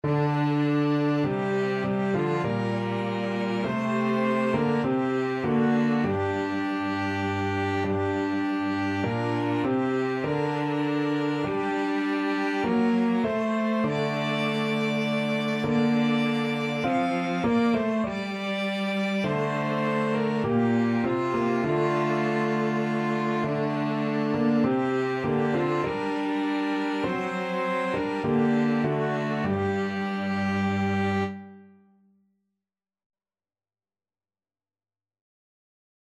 Violin
Clarinet
Cello
Piano
Traditional Music of unknown author.
G minor (Sounding Pitch) (View more G minor Music for Flexible Ensemble and Piano - 3 Players and Piano )
2/2 (View more 2/2 Music)
Christian (View more Christian Flexible Ensemble and Piano - 3 Players and Piano Music)